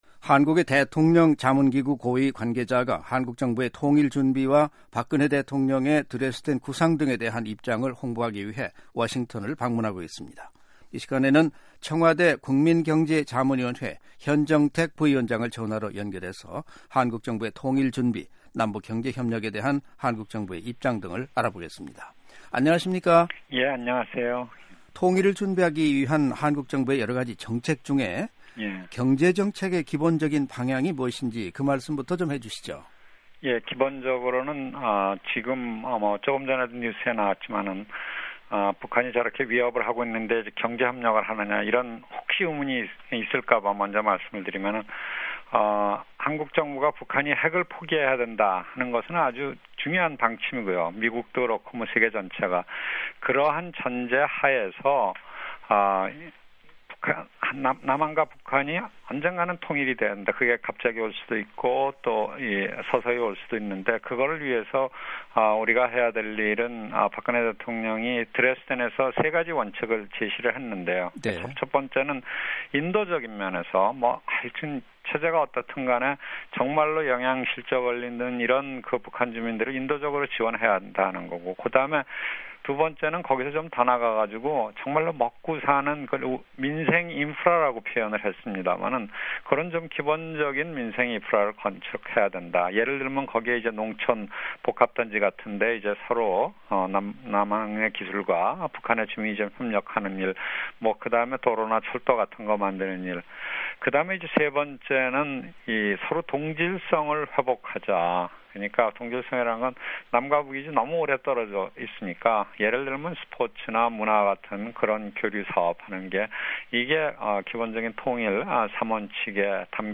한국 청와대 국민경제자문위원회 현정택 부위원장을 전화로 연결해 한국 정부의 통일 준비, 남북 경제협력에 대한 한국 정부의 입장 등을 알아보겠습니다.